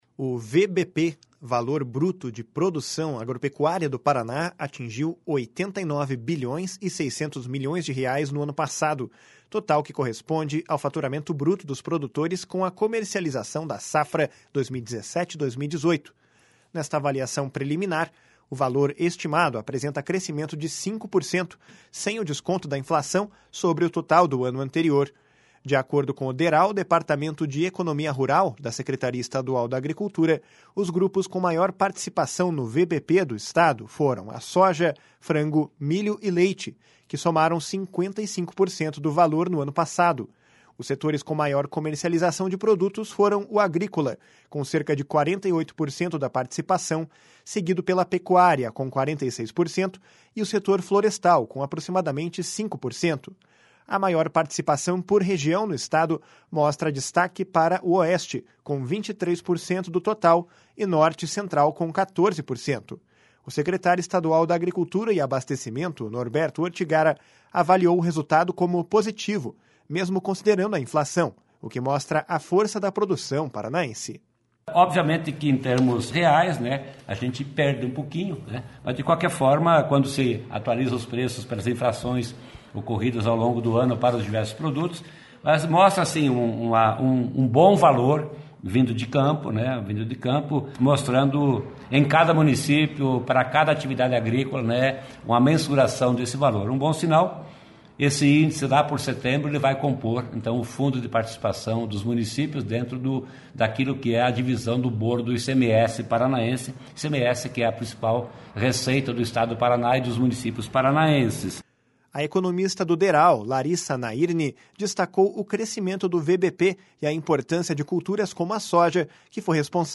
O secretário estadual da Agricultura e Abastecimento, Norberto Ortigara, avaliou o resultado como positivo, mesmo considerando a inflação, o que mostra a força da produção paranaense. // SONORA NORBERTO ORTIGARA //